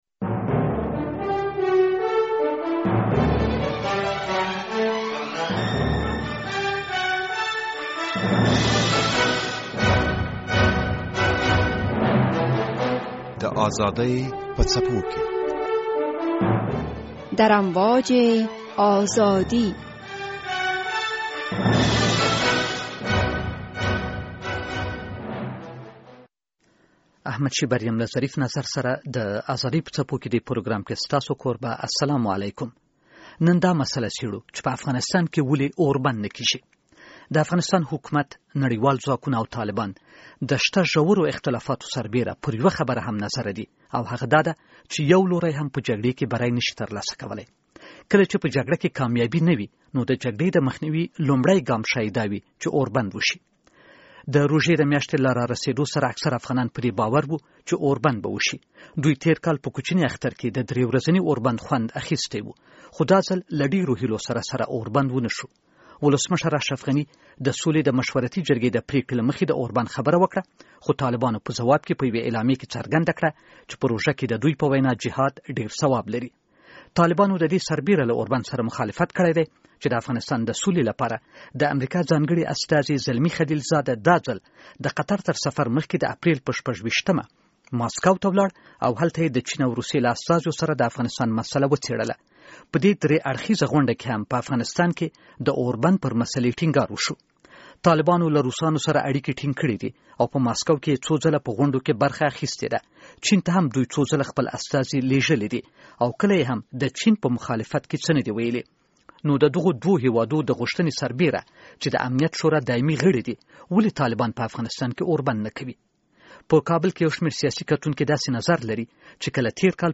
د «ازادي په څپو کې» د بحث لومړۍ برخه